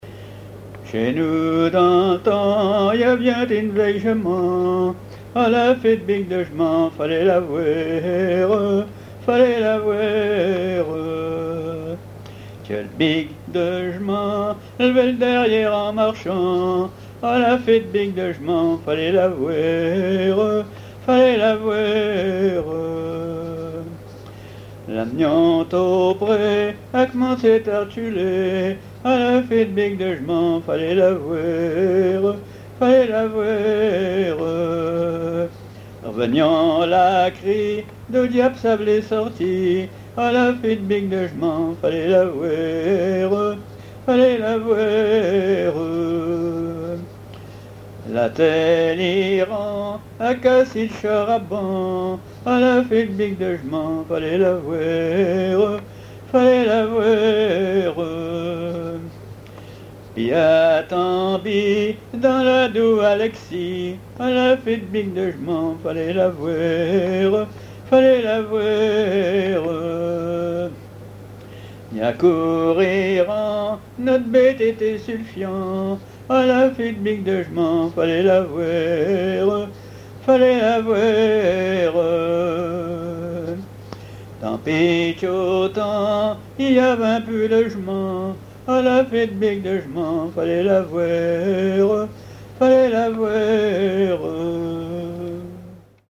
chansons à ripouner ou à répondre
Pièce musicale inédite